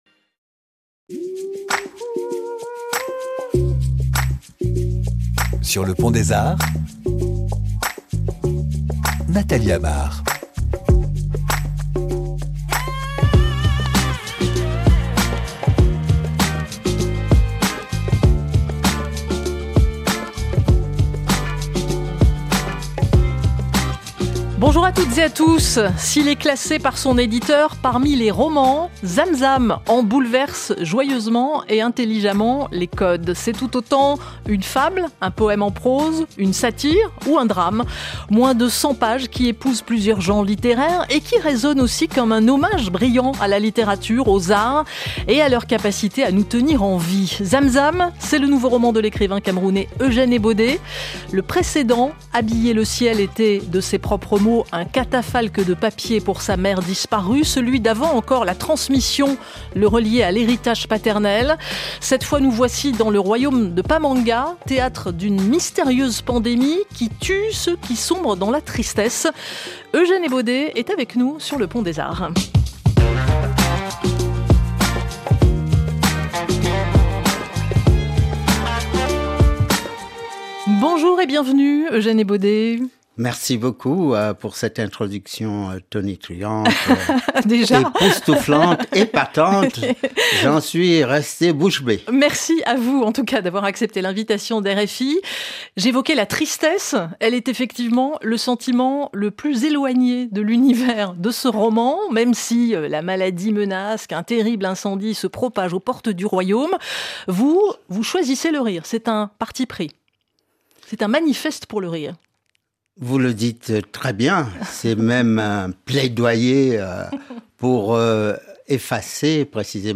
Eugène Ébodé, écrivain et auteur du roman Zam-Zam, est l'invité de Sur le pont des arts.